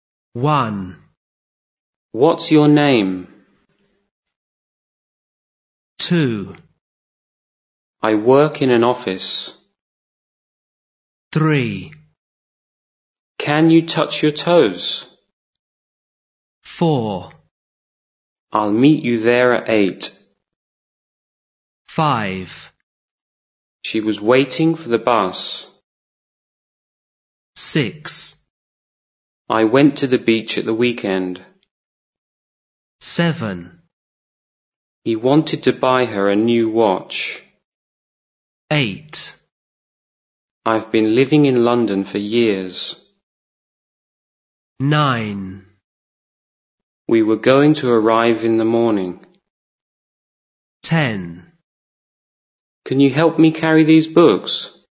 1. Sentence stress.
Listen to the sentences and questions. How many stressed words are there in each one?
Pay attention to the stress and rhythm of each one.